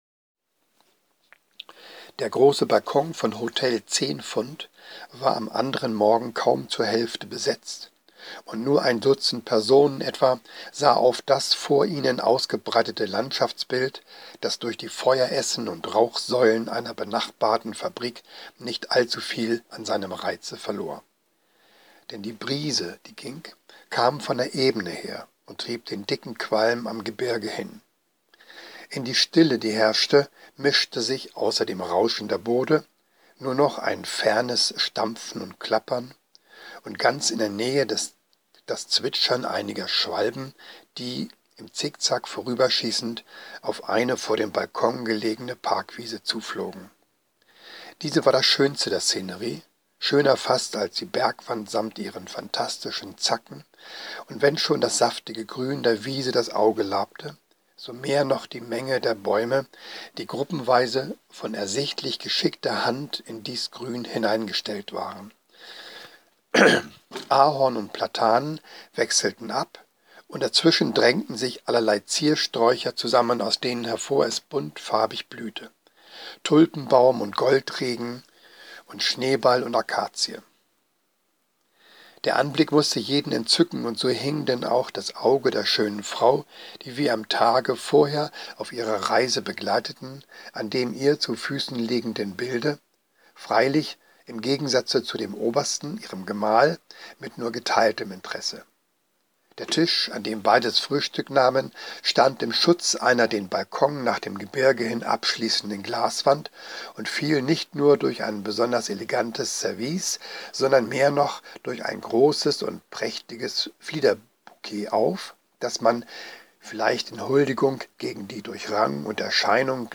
Zwei kleine Leseproben, mehr oder weniger zufällig ausgewählt.
Gelesen leider mit verschnupfter Stimme.